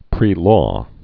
(prē)